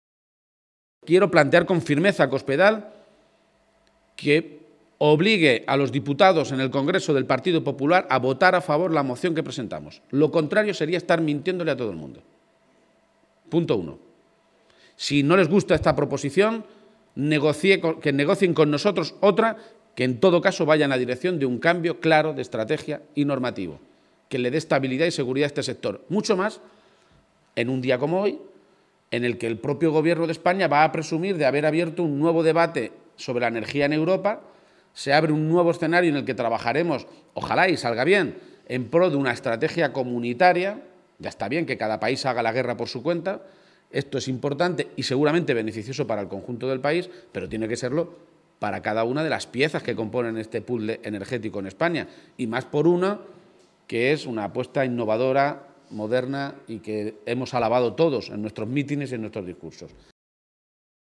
El candidato a Presidente de Castilla-La Mancha hacía estas declaraciones en una comparecencia ante los medios de comunicación en la ciudad minera, después de mantener una reunión con el Comité de Empresa de Elcogás, donde ha recibido información de primera mano de cómo está la negociación que están llevando a cabo tanto con el ministerio de industria, como con la propia empresa, que ha anunciado el cierre en diciembre si el Estado no cambia el marco que regula las ayudas públicas a este tipo de industrias energéticas.